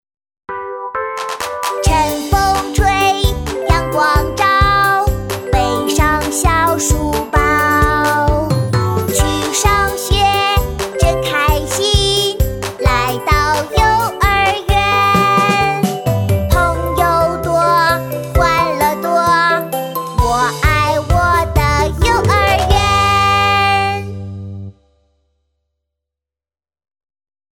• 女S155 国语 女声 歌曲翻唱唱歌-男童-活泼、可爱 时尚活力|亲切甜美|素人